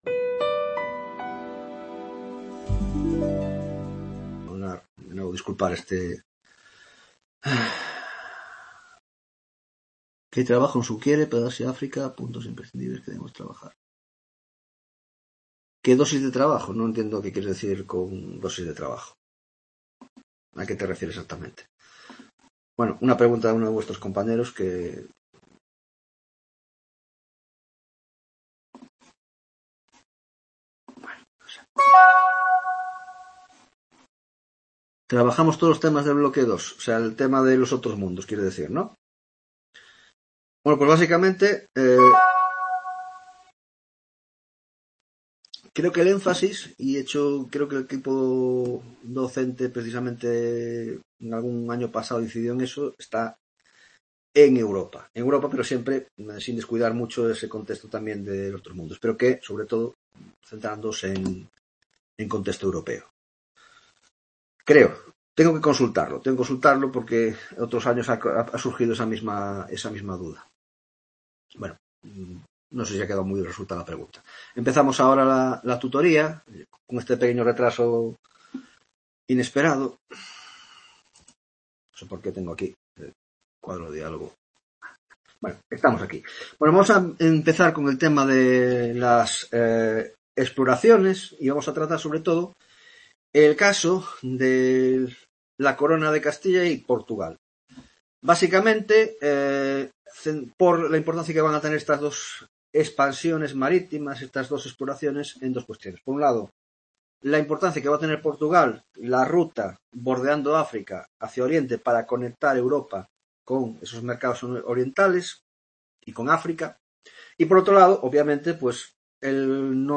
3ª Tutoria Historia Moderna (Grado de Antropologia Social y Cultural): Descubrimiento y Expansión portuguesa y castellana